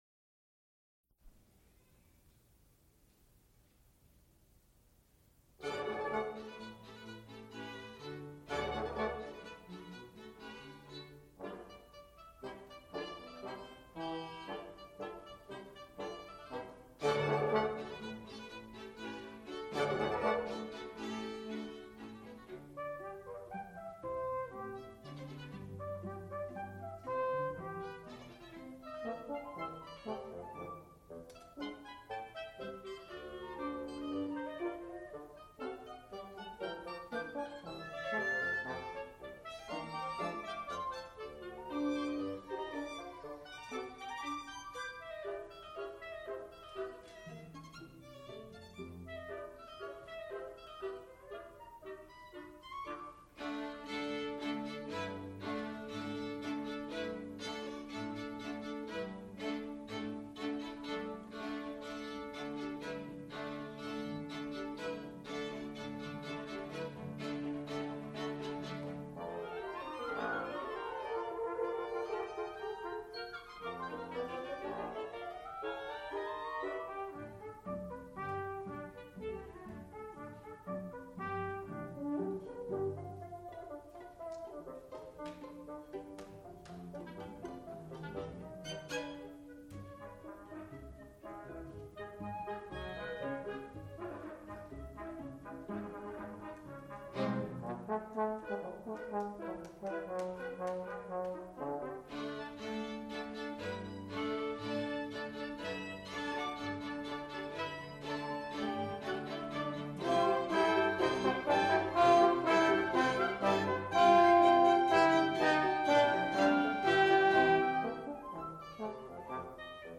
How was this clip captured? • 3 audiotape reels : analog, quarter track, 7 1/2 ips ; 7 & 12 in. • musical performances